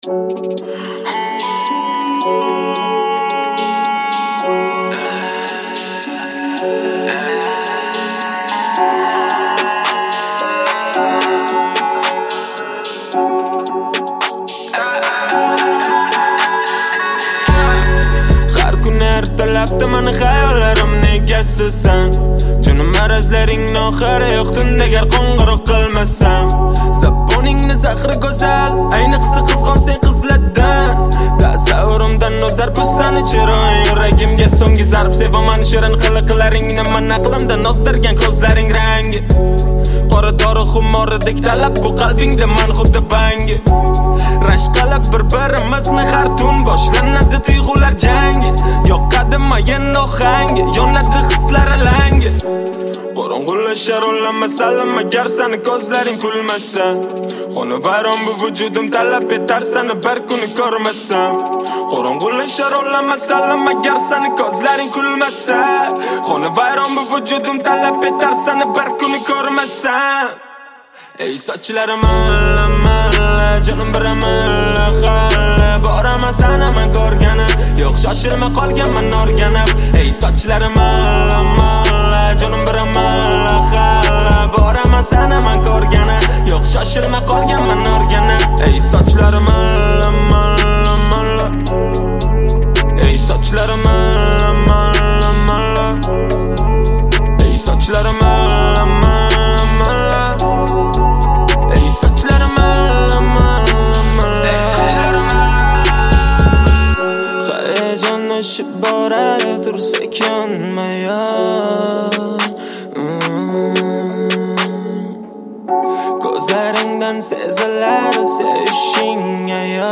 • Жанр: Узбекская музыка